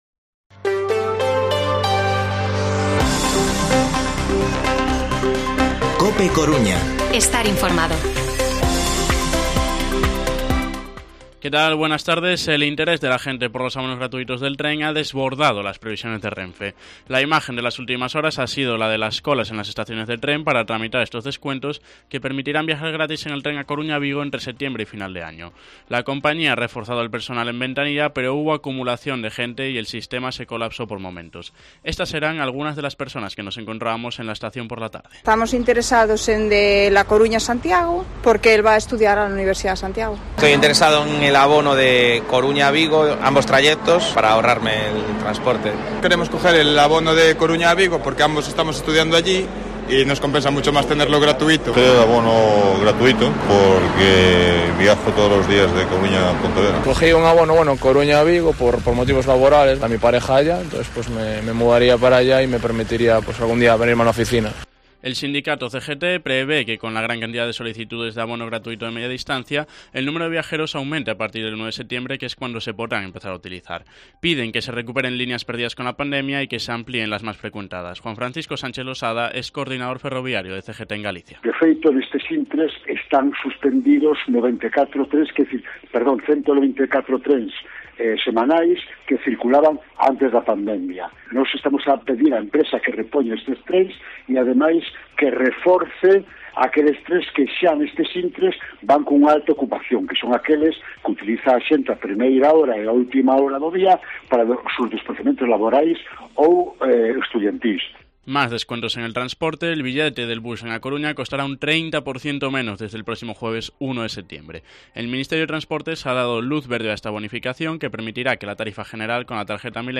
Informativo Mediodía COPE Coruña jueves, 25 de agosto de 2022 14:20-14:30